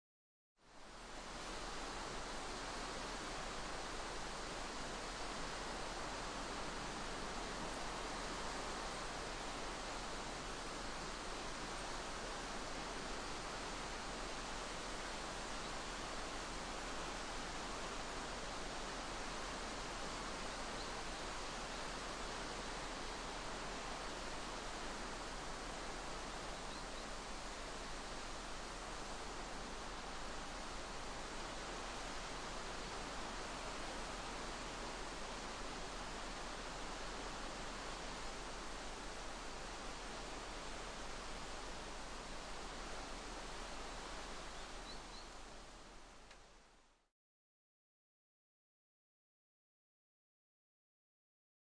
Mountain stream.mp3